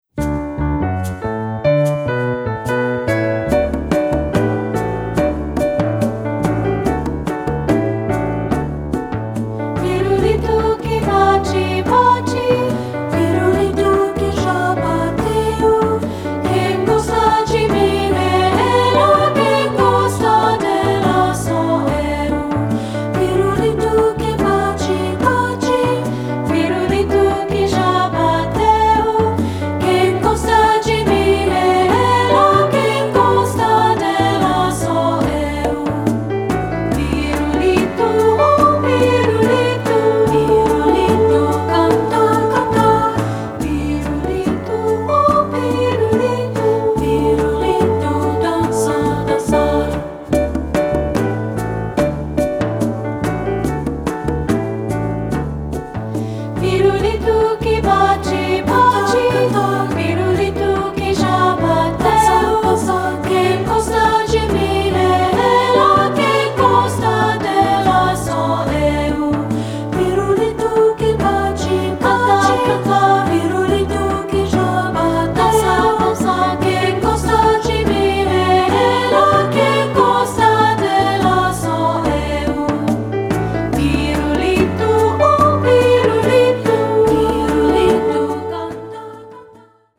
General Music Elementary Choral & Vocal Multicultural Choral
Brazilian Folk Song